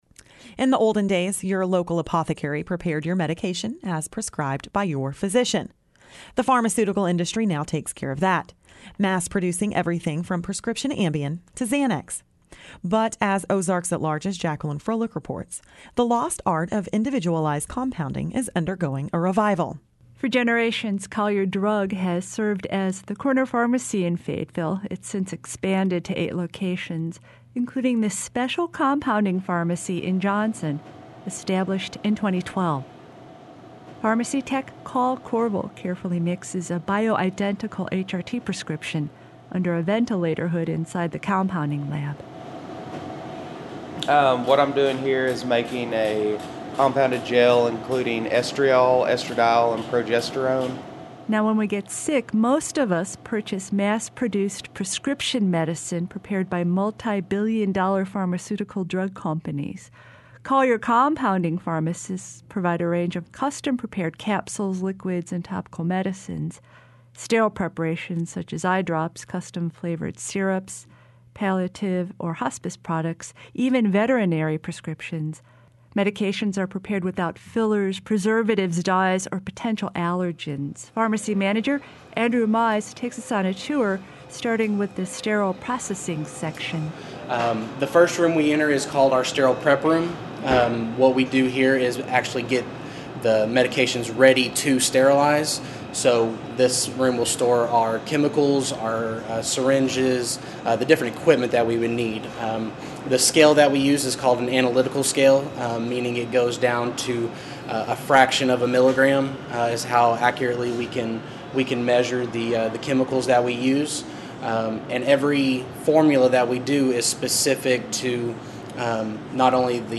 Transition Music: